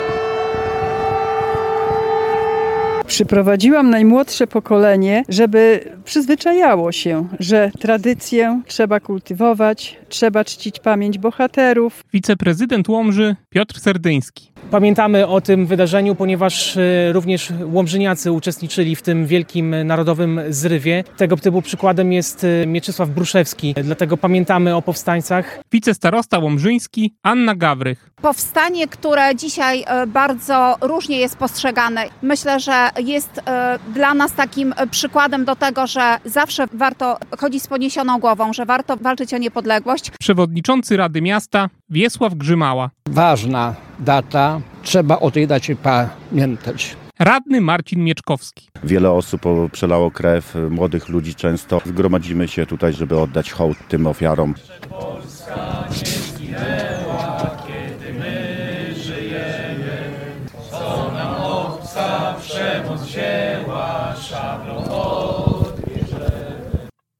Gdy wybiła godzina „W” tradycyjnie rozbrzmiały syreny, a zgromadzenie zakończyło się uroczystym odśpiewaniem „Mazurka Dąbrowskiego”.
Więcej w naszej relacji: